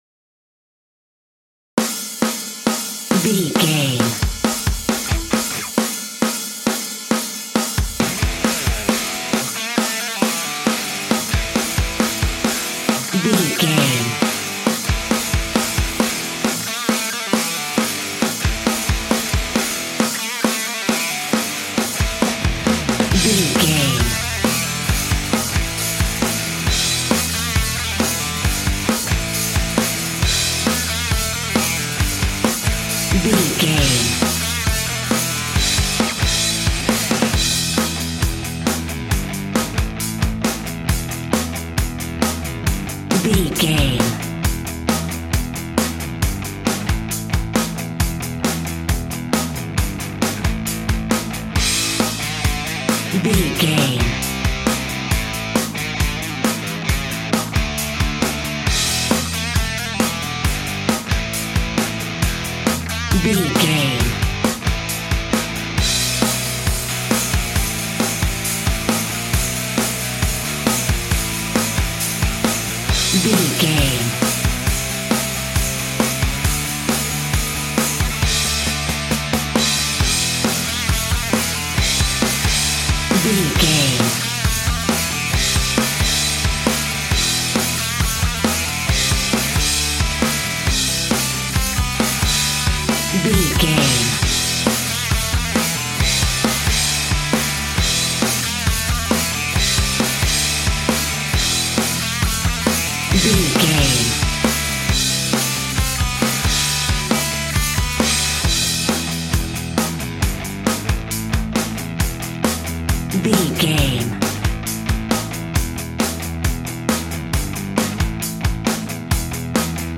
Epic / Action
Aeolian/Minor
hard rock
heavy metal
blues rock
distortion
rock guitars
Rock Bass
Rock Drums
heavy drums
distorted guitars
hammond organ